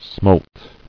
[smolt]